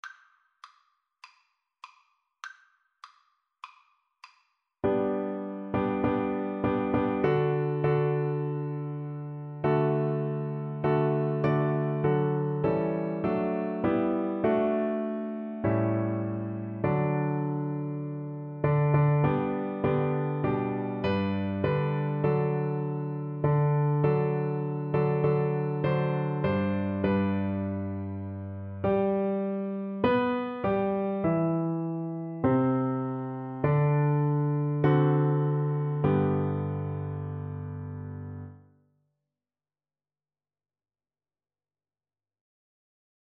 G major (Sounding Pitch) (View more G major Music for Cello )
2/2 (View more 2/2 Music)
Classical (View more Classical Cello Music)